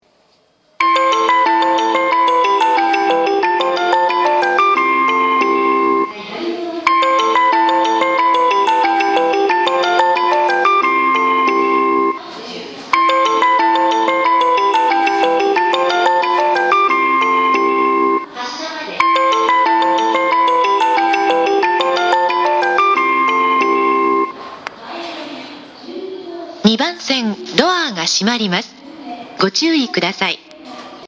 発車メロディー
4コーラスです。